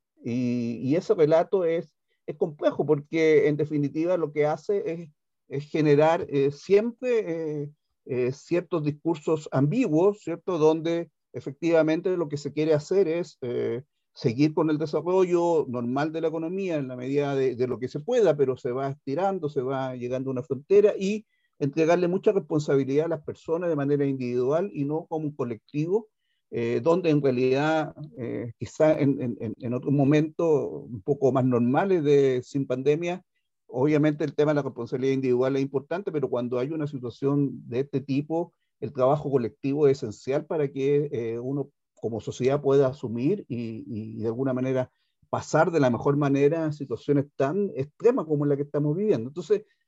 En conversación con Nuestra Pauta